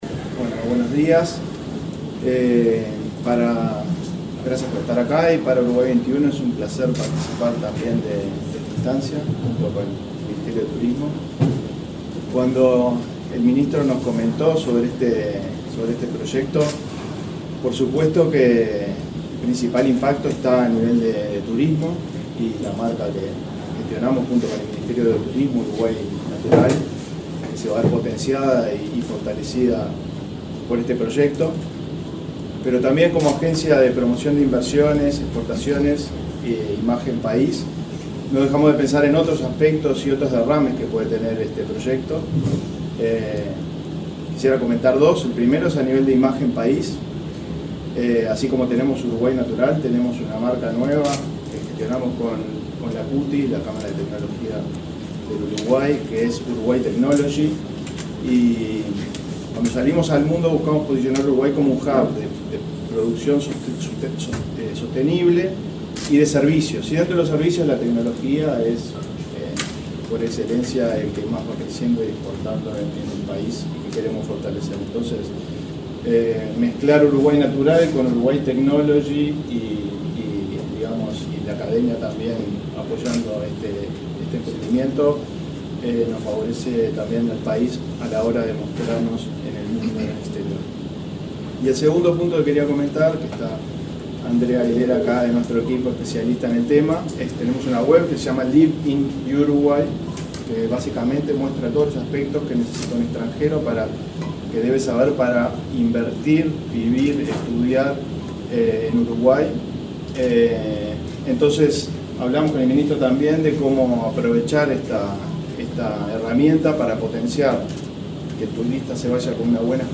Palabras del vicedirector ejecutivo de Uruguay XXI, Eduardo Rodríguez
Palabras del vicedirector ejecutivo de Uruguay XXI, Eduardo Rodríguez 11/11/2024 Compartir Facebook X Copiar enlace WhatsApp LinkedIn El vicedirector ejecutivo de Uruguay XXI, Eduardo Rodríguez, participó, este lunes 11 en el Laboratorio Tecnológico del Uruguay, en el lanzamiento de iUruguay Open Call, una iniciativa con la que se busca desarrollar una plataforma digital de información turística de Uruguay.